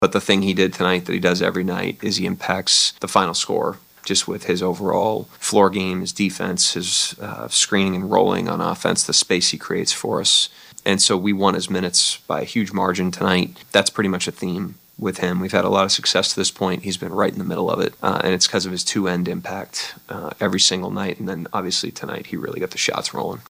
Thunder head coach Mark Daigneault talks about how Holmgren affects the game.